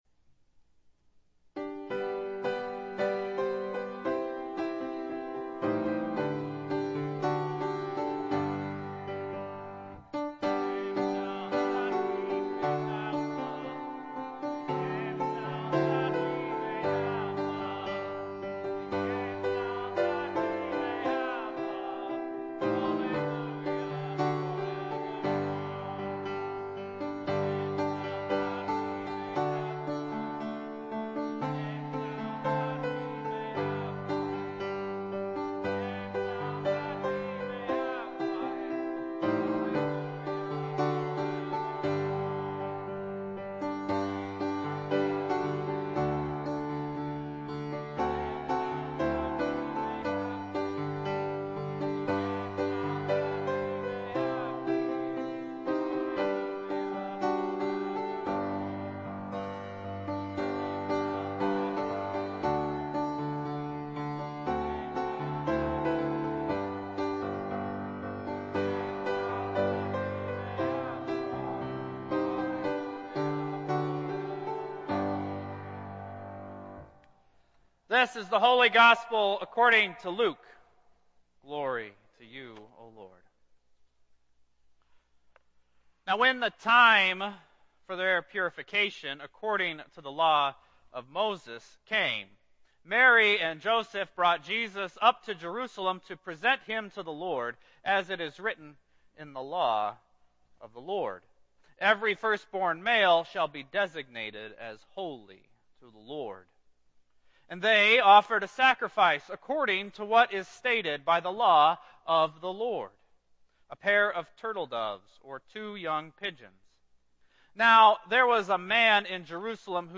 Worship Services | Christ The King Lutheran Church
Sermon Notes